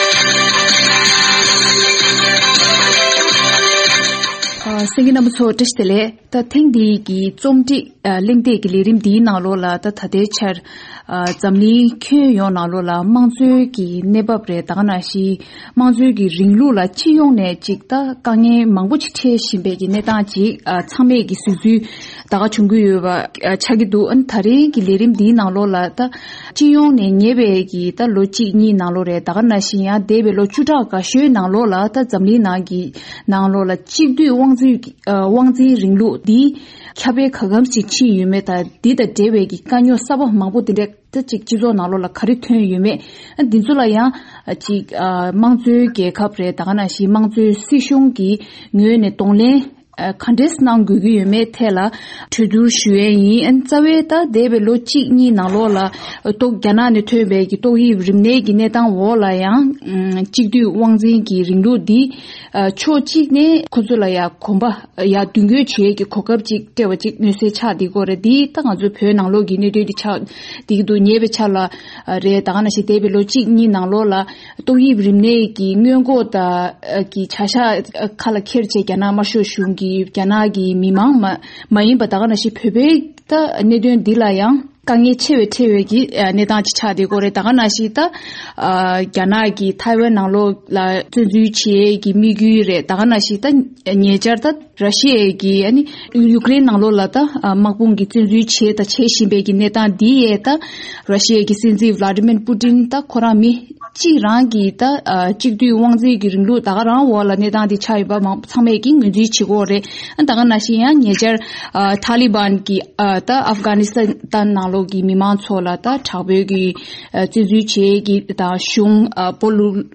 དཔྱད་གླེང་བ།